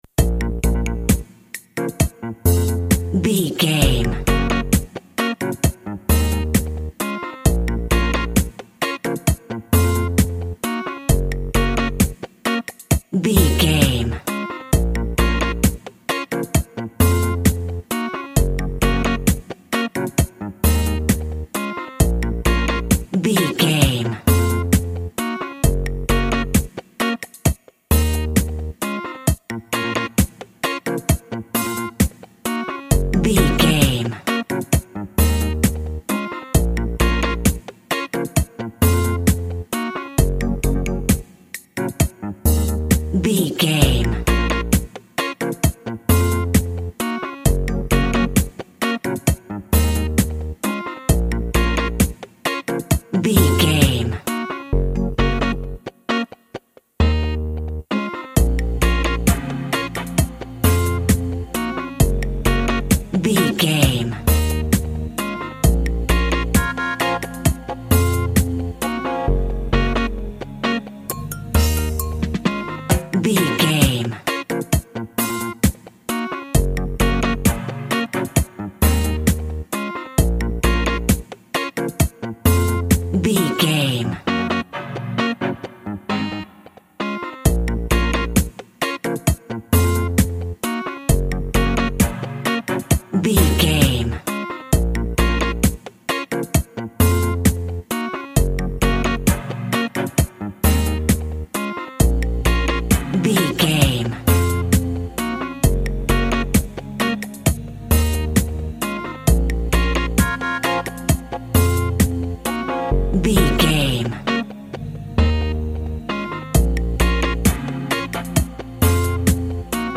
Also with small elements of Dub and Rasta music.
Ionian/Major
B♭
tropical
drums
bass
guitar
piano
brass
steel drum